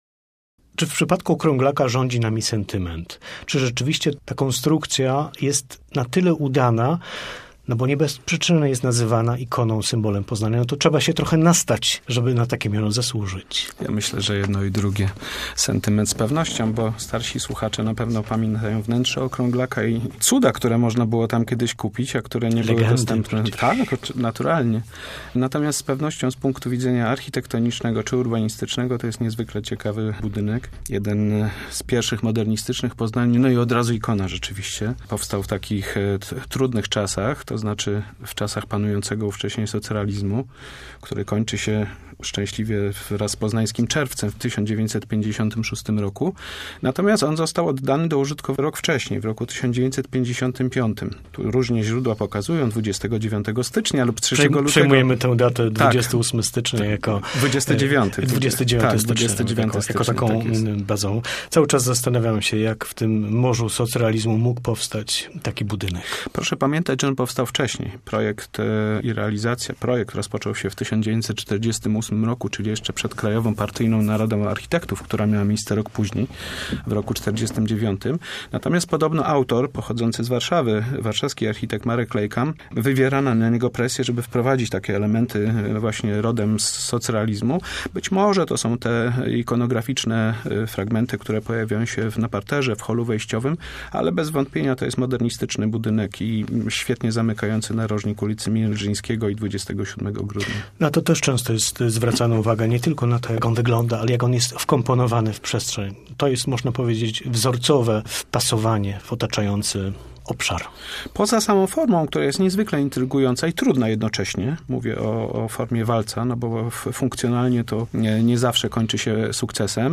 Rozmowa z okazji 70. lat Okrąglaka